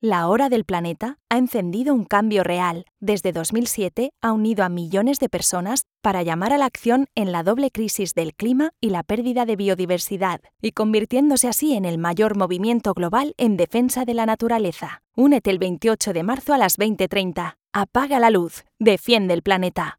Cuña radio